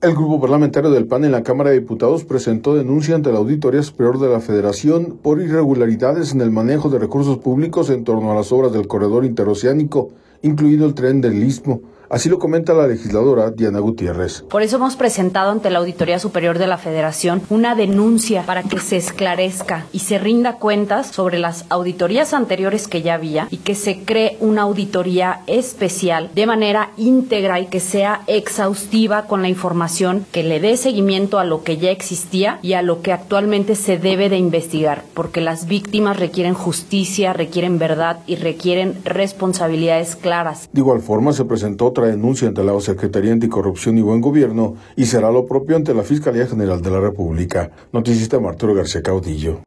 El Grupo Parlamentario del PAN en la Cámara de Diputados presentó denuncia ante la Auditoría Superior de la Federación por irregularidades en el manejo de recursos públicos en torno a las obras del Corredor Interoceánico, incluido el tren del Istmo, así lo comenta la legisladora Diana Gutiérrez.